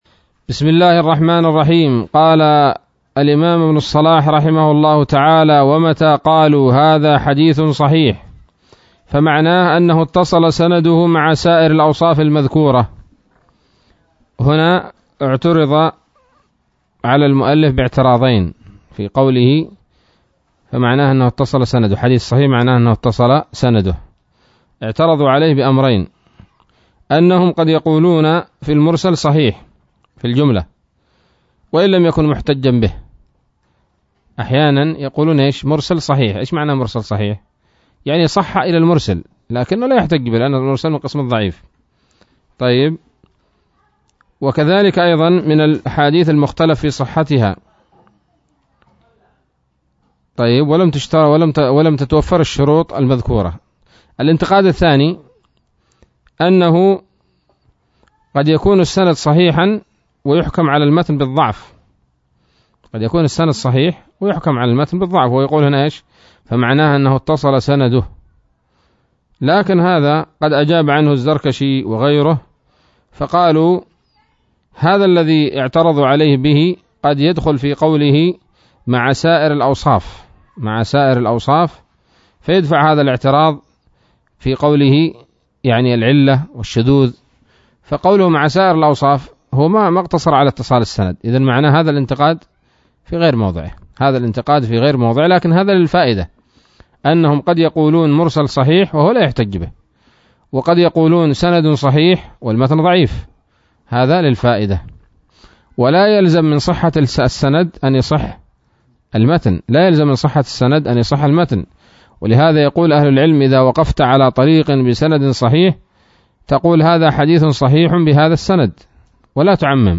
الدرس السادس من مقدمة ابن الصلاح رحمه الله تعالى